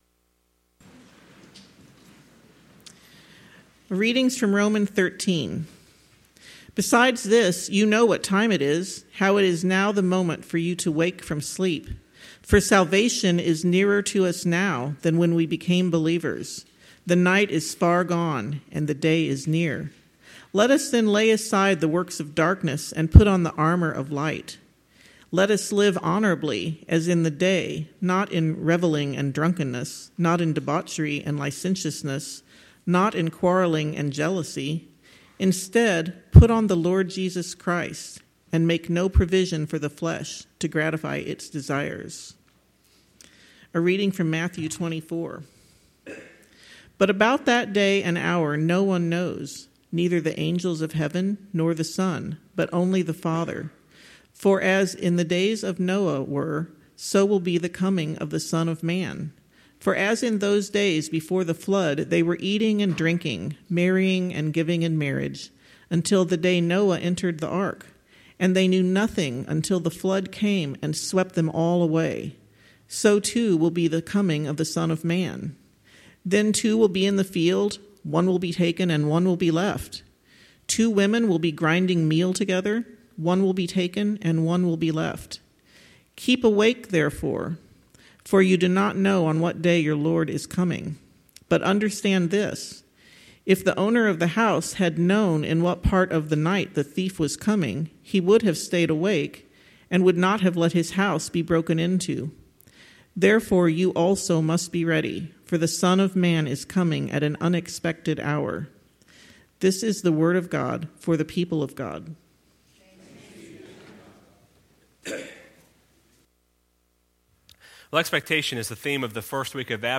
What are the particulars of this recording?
Waiting on Hope Passage: Romans 13:11-14; Matthew 24:36-44 Service Type: Sunday Morning « We Believe